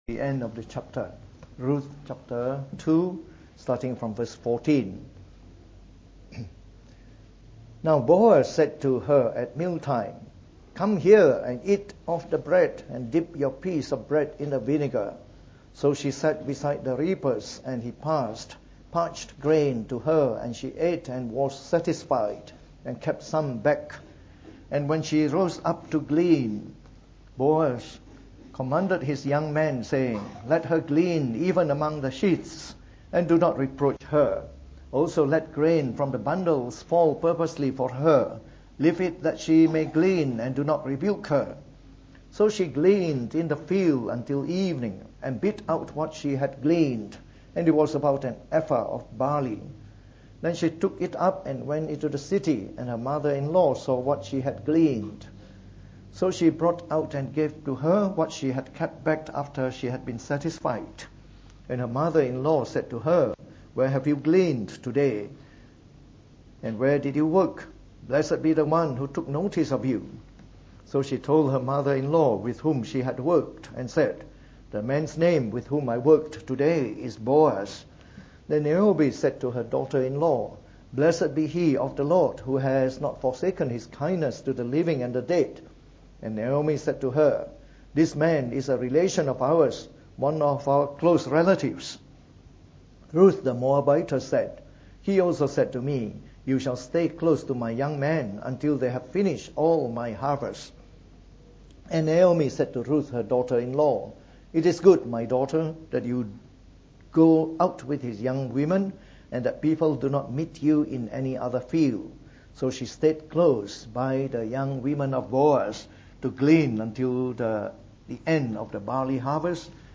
From our series on the Book of Ruth delivered in the Morning Service.